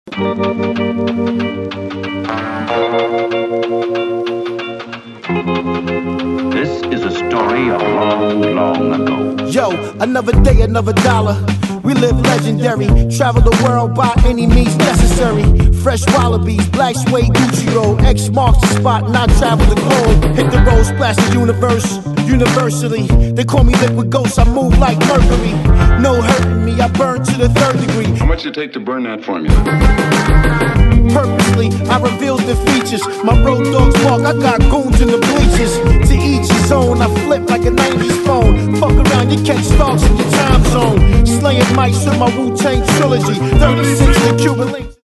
Genre : Downtempo